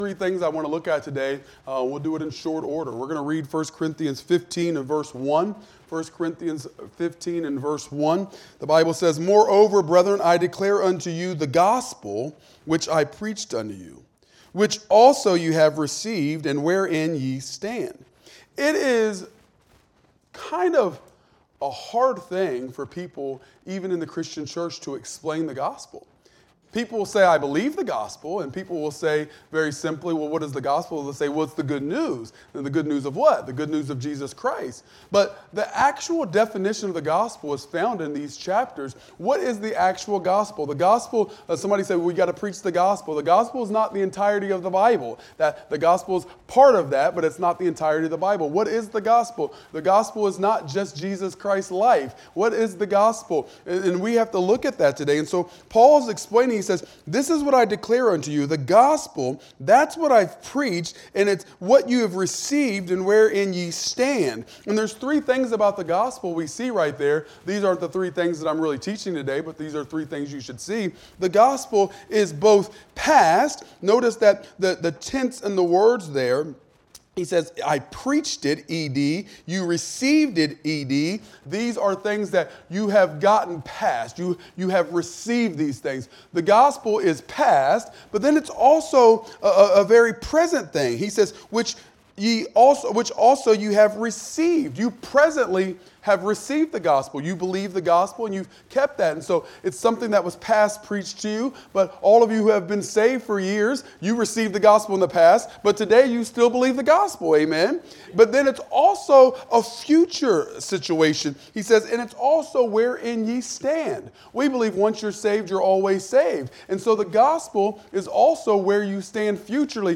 Wednesday Noon Bible Study